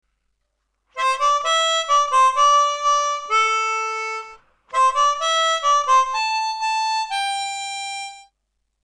C Major Chromatic (Hohner CX12)
Middle section